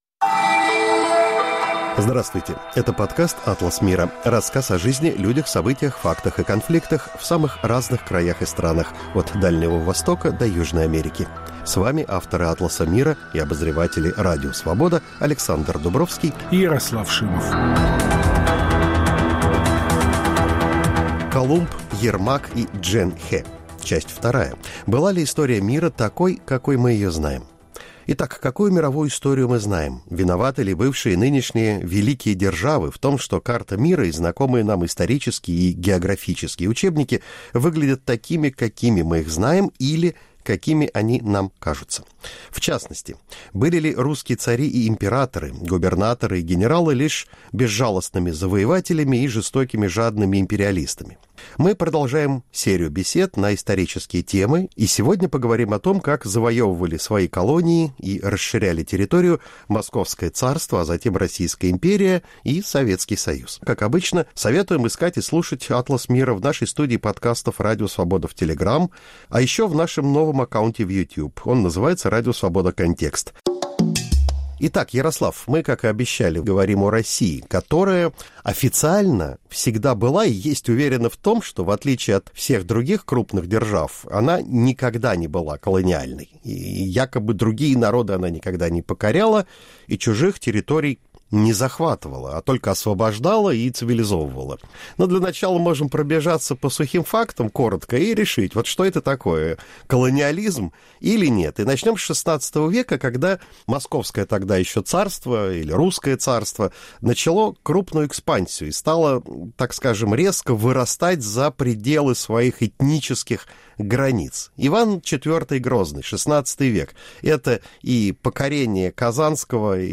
Мы продолжаем цикл бесед о переоценках истории. В новом выпуске – беседа о том, как завоевывали свои колонии и расширяли территорию Московское царство, а затем Российская империя и СССР.